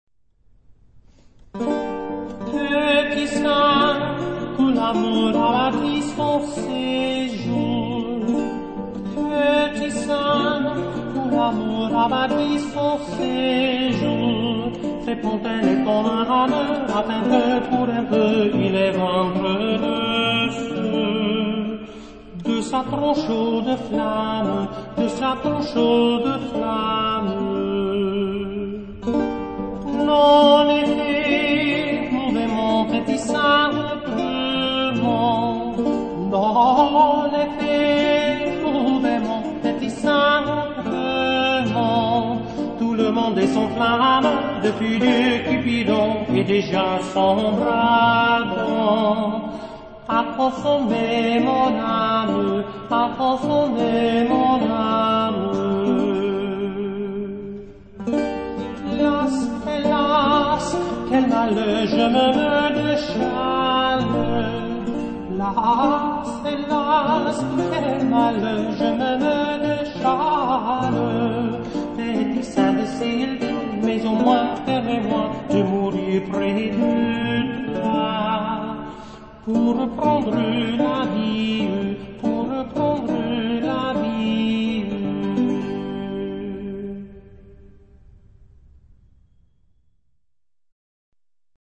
chamber music
classical